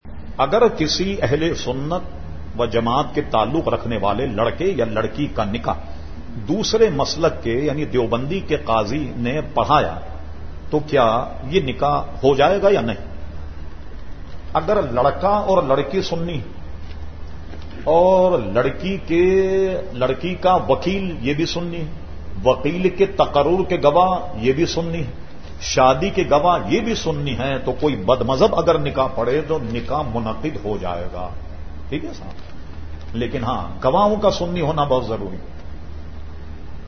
Q/A Program held on Tuesday 31 August 2010 at Jamia Masjid Ameer e Hamza Nazimabad Karachi.